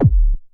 Kicks
07_Kick_03_SP.wav